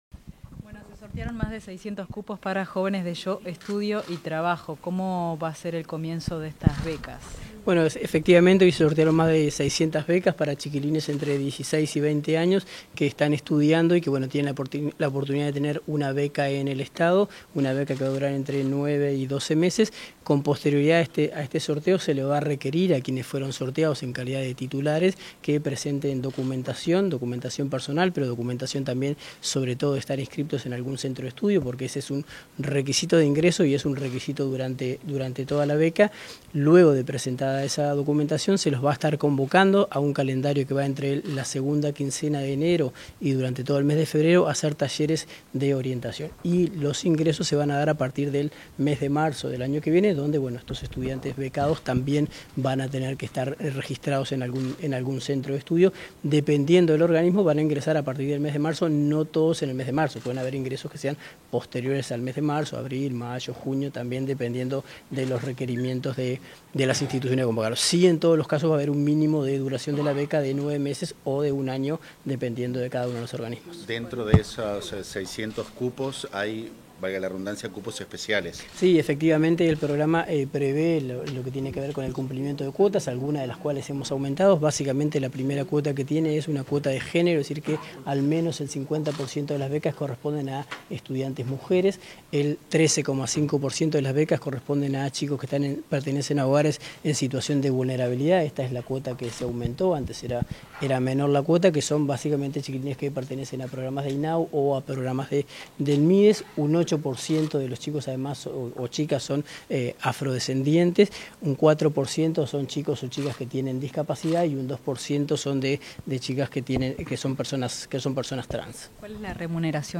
Palabras del director nacional de Empleo, Daniel Pérez
Declaraciones a la prensa del director nacional de Empleo, Daniel Pérez